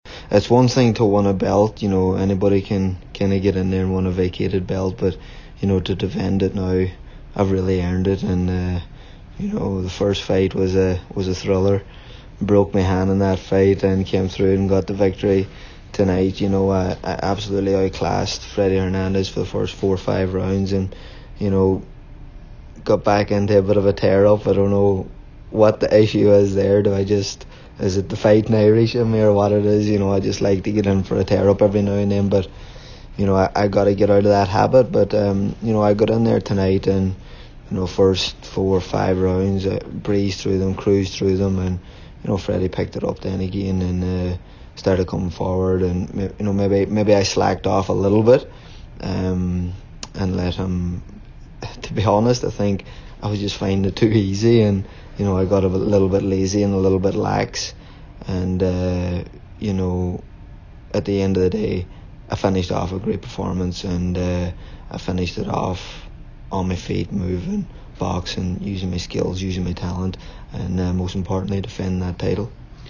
Quigley spoke after the fight…